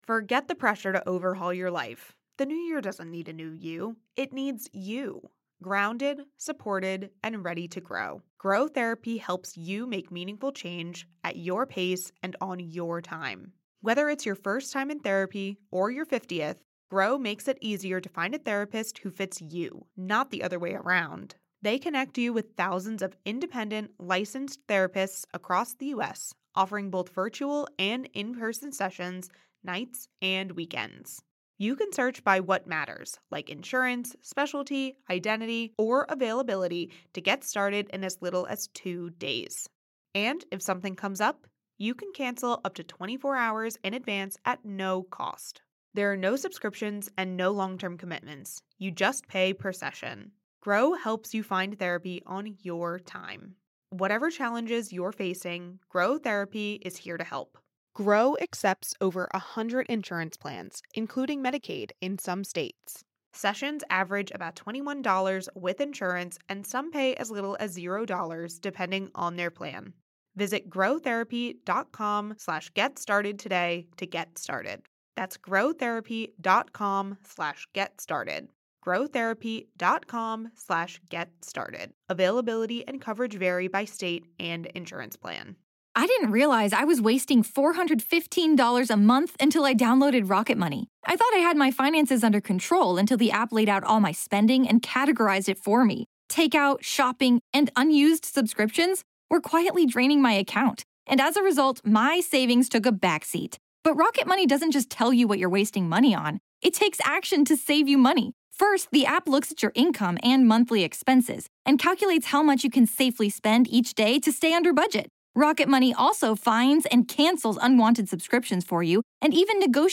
*BONUS* A Conversation with Pamela Smart